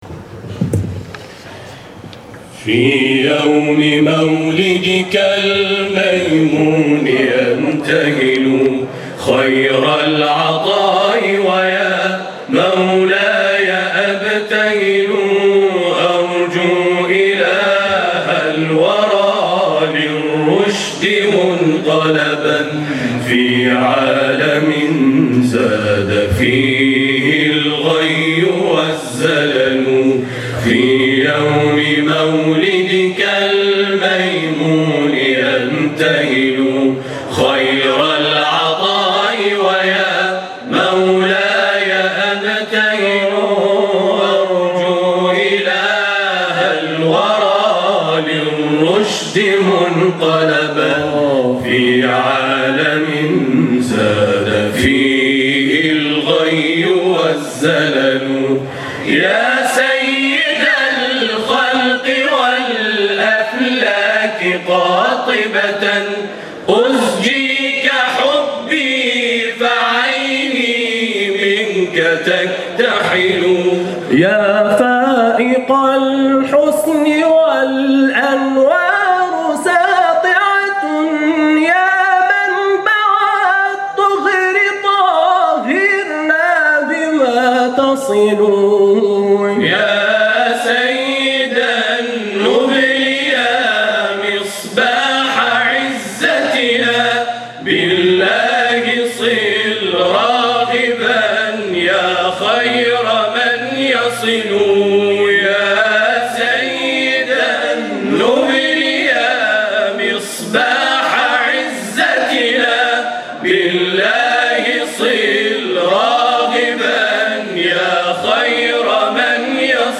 صوت | هم‌خوانی گروه تواشیح نورالزهرا(س) خوزستان
گروه تواشیح نورالزهرا خوزستان، امروز 22 دی، در آیین افتتاحیه چهل‌و‌پنجمین دوره مسابقات سراسری قرآن، به اجرای برنامه پرداخت.